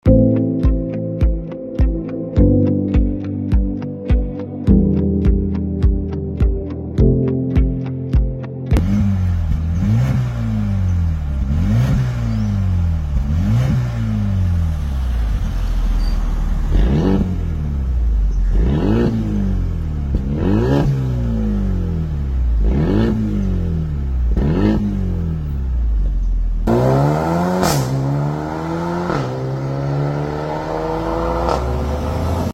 Mercedes Benz CLA35 AMG Upgrade RES Racing Exhaust System Sound Test!!